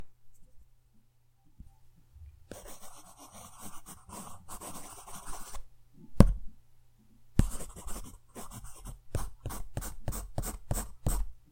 描述：在平台上揉我的手指
Tag: 涂鸦 绘图 纸板 S 铅笔